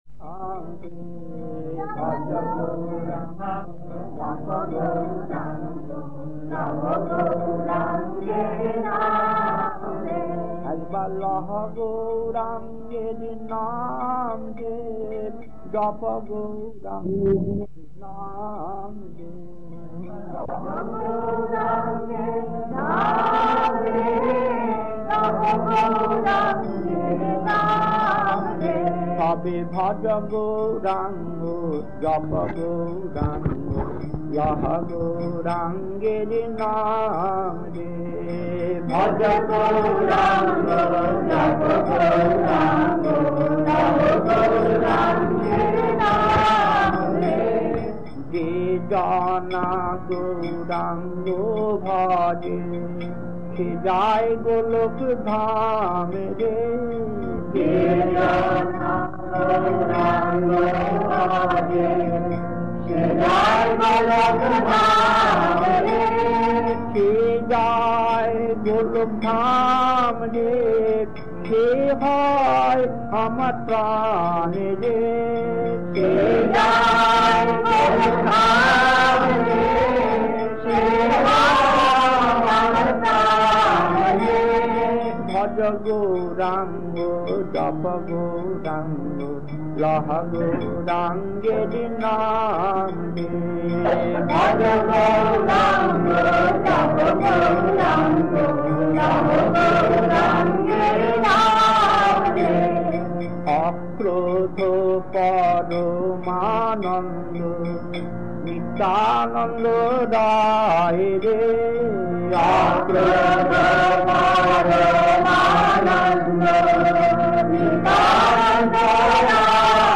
Kirtan C4-1 Rockdale, Bombay, Late 70's, 29 minutes 1.